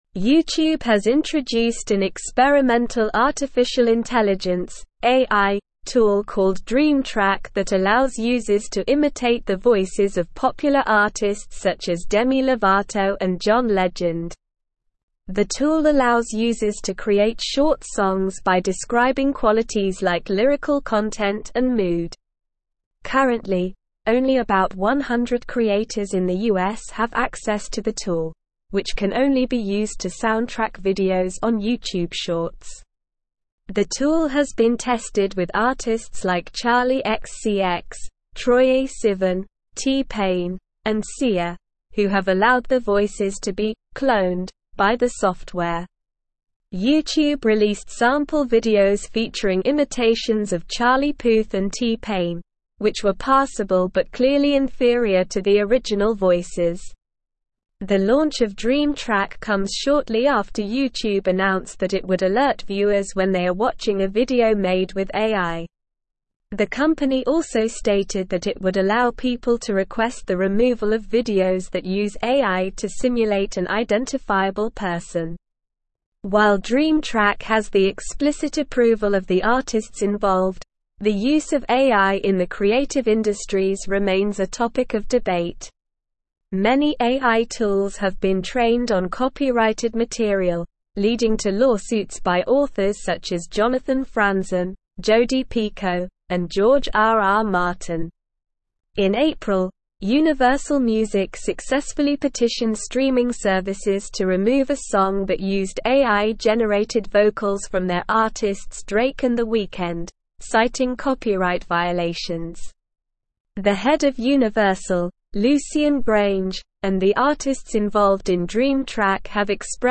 Slow
English-Newsroom-Advanced-SLOW-Reading-YouTube-Unveils-Dream-Track-AI-Tool-Imitates-Artists-Voices.mp3